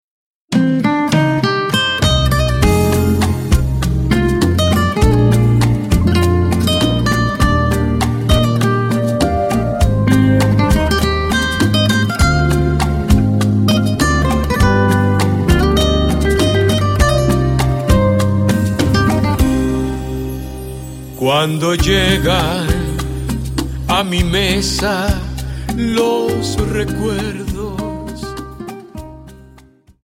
Dance: Rumba